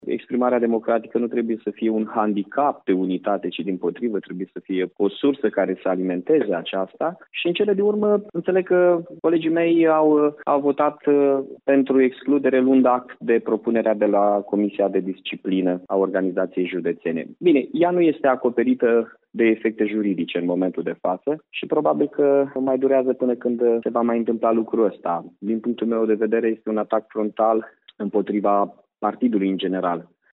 Tot la Radio Iaşi, primarul Mihai Chirica a invocat mai multe probleme de procedură pe care le va contesta odată ce va primi motivarea deciziei: